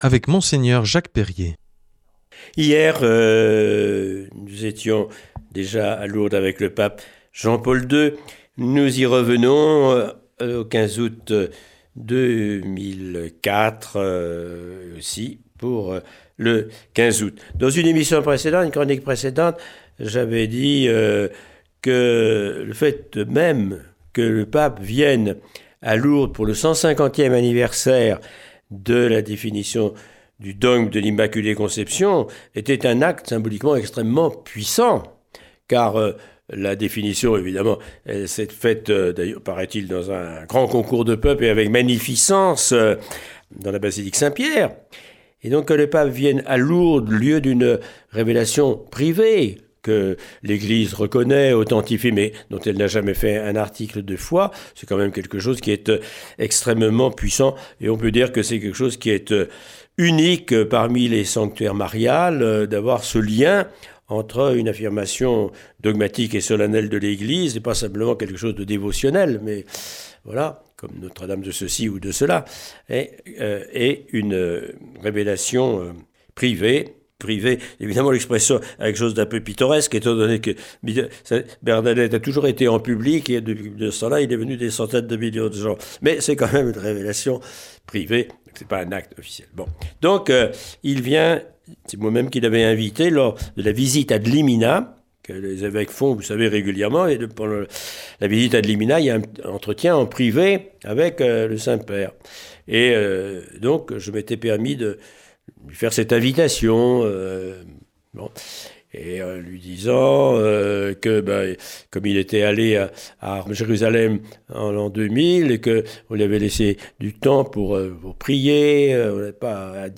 Retour sur la deuxième visite du pape Jean-Paul II à Lourdes avec les moments forts de ctte visite qui nous sont relatés par Mgr Jacques Perrier.